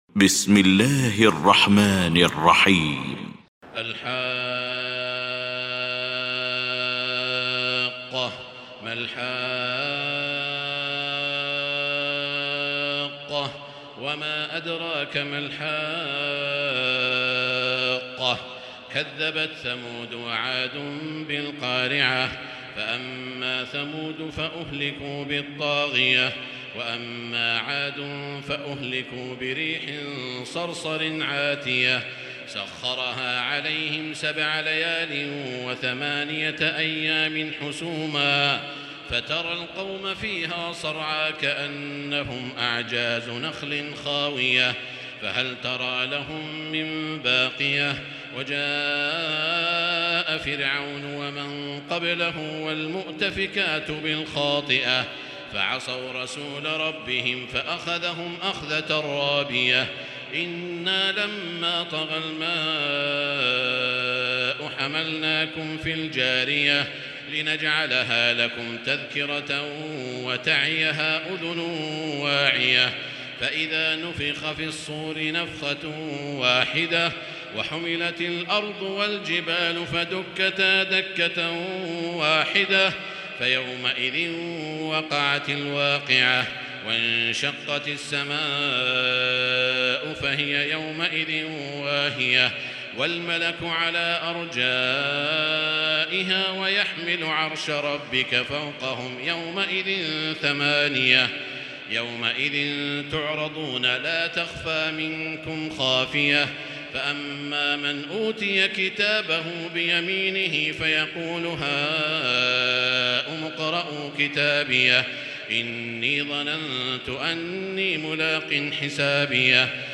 المكان: المسجد الحرام الشيخ: سعود الشريم سعود الشريم الحاقة